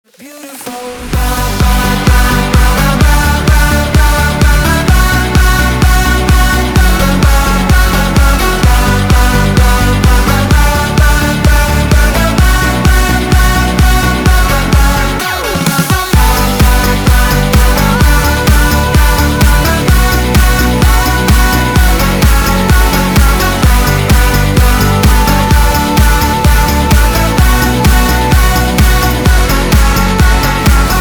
• Качество: 320, Stereo
dance
EDM
progressive house